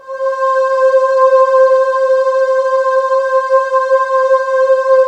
Index of /90_sSampleCDs/USB Soundscan vol.28 - Choir Acoustic & Synth [AKAI] 1CD/Partition C/10-HOOOOOO
HOOOOOO C4-L.wav